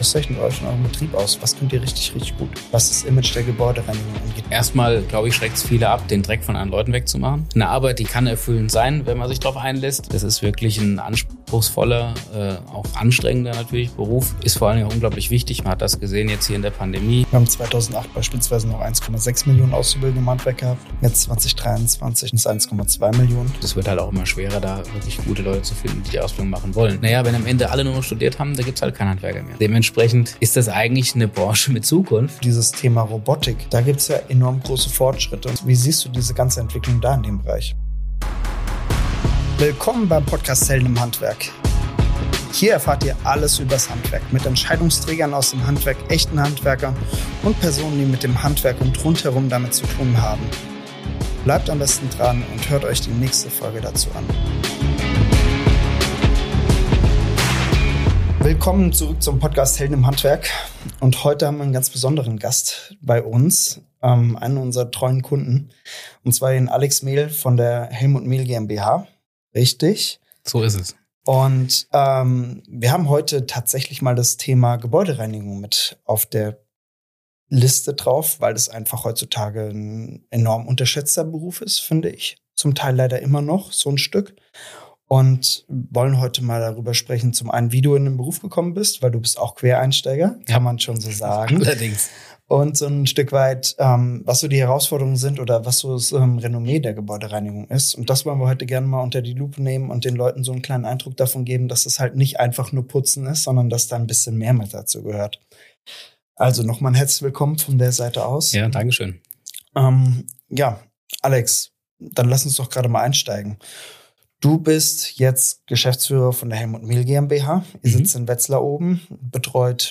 Warum die Branche oft unterschätzt wird, welche Probleme sich im Laufe der Zeit entwickelt haben und wieso es höchste Zeit für mehr Anerkennung ist. All das und mehr gibt’s in diesem ehrlichen und spannenden Gespräch.